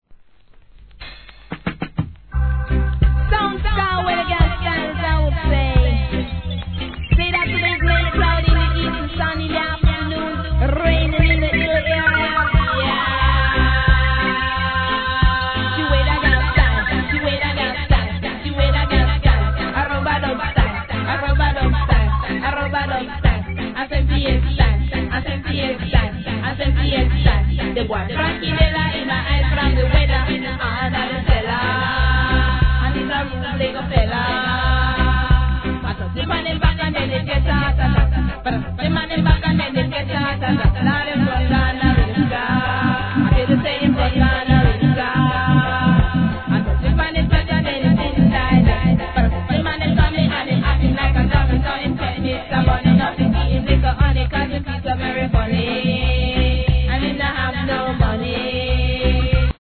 フィメールのDeeJayスタイル!!